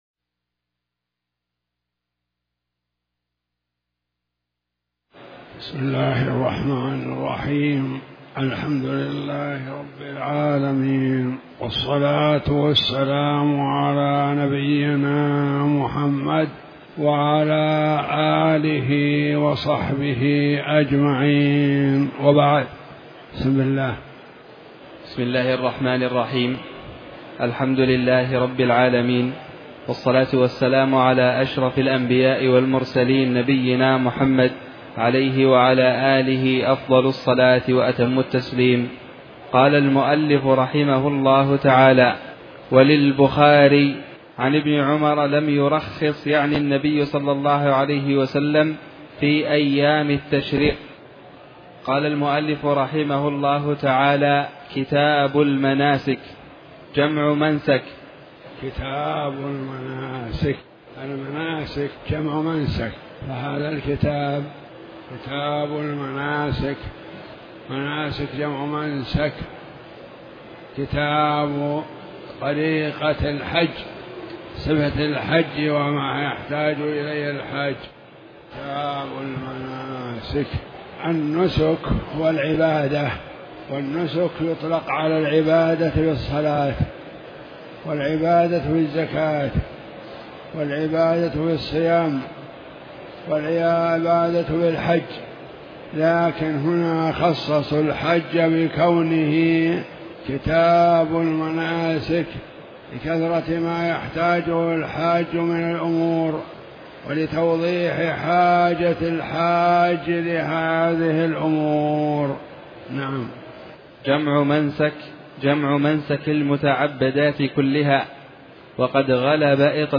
تاريخ النشر ٦ ذو الحجة ١٤٣٩ هـ المكان: المسجد الحرام الشيخ